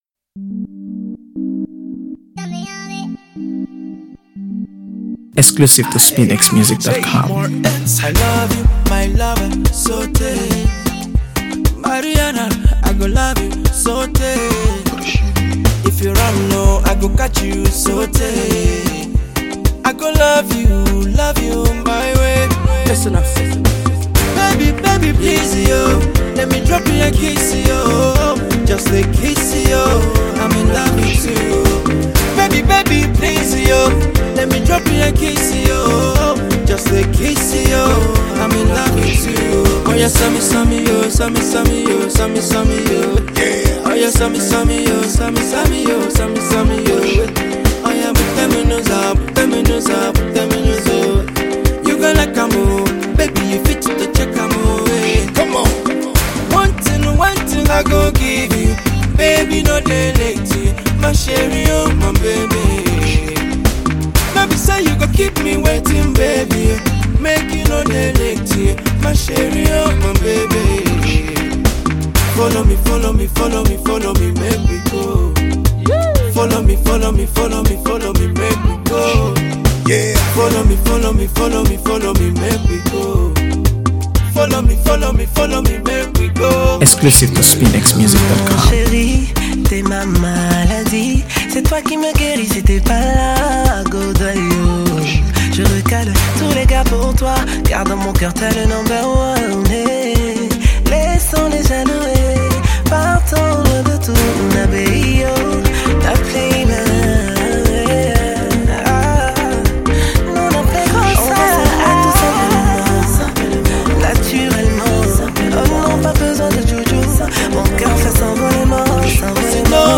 By on Afrobeat
This is a love song.